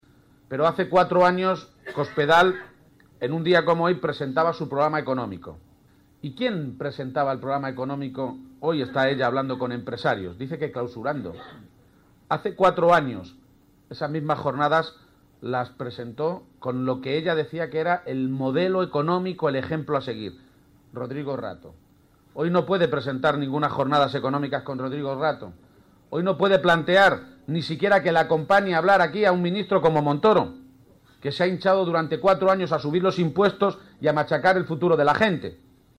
Audio Page en La Solana 2